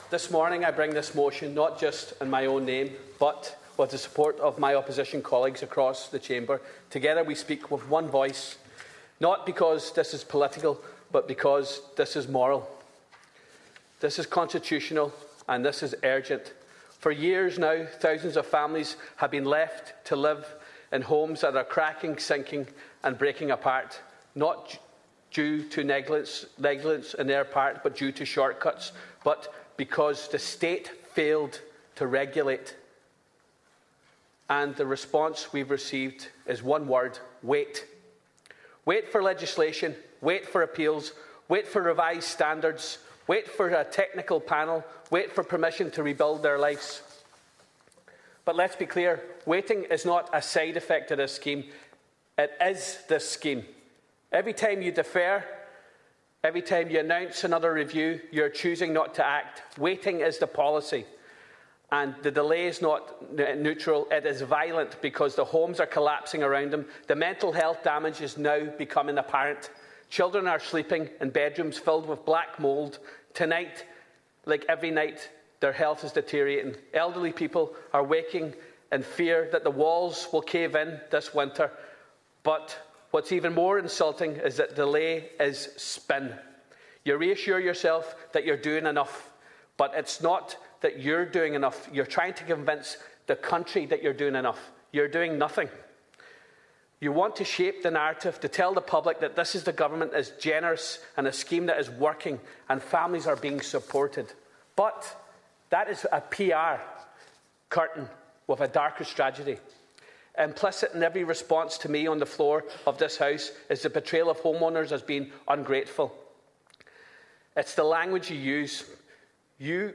100% Redress Deputy Charles Ward brought a landmark motion before the Dail today, backed by the entire opposition, which called for reform of the Defective Concrete Block Redress Scheme.